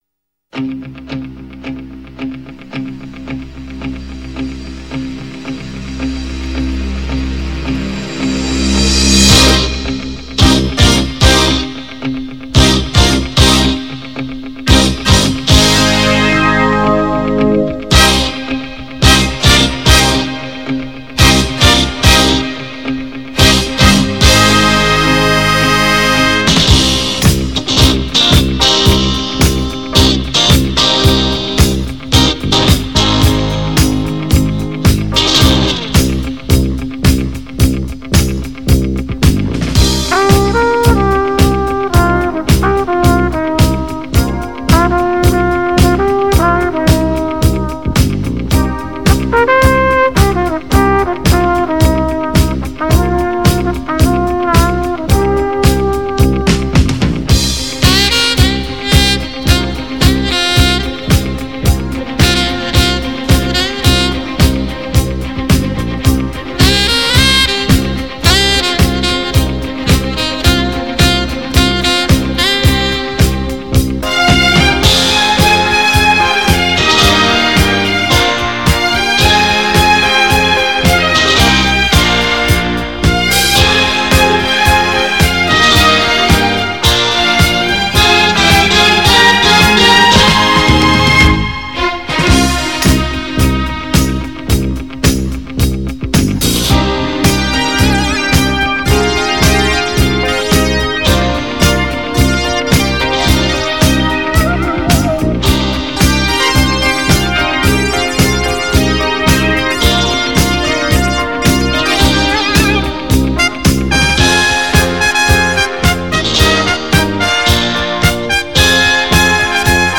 专辑格式：DTS-CD-5.1声道
最强的镭射音响试听唱片，音响效果清晰，最好的发烧试音天碟，强势出击，靓声绝顶！